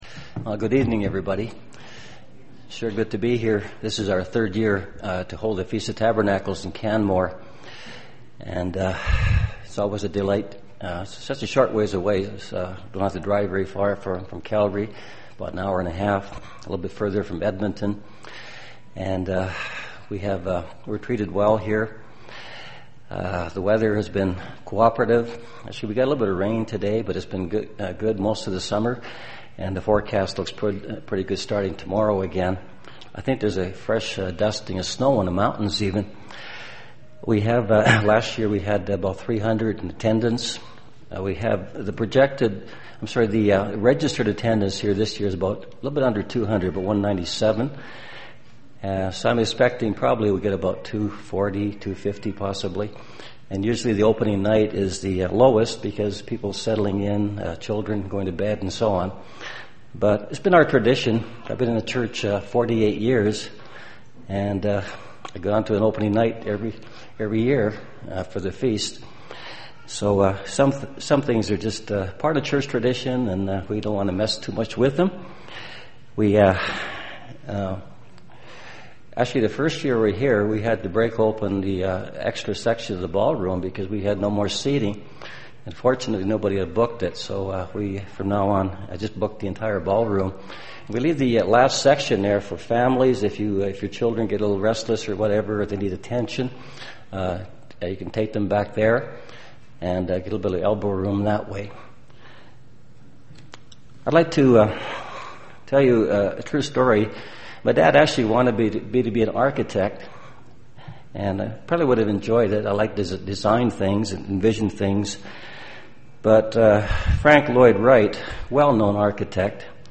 This sermon was given at the Canmore, Alberta 2013 Feast site.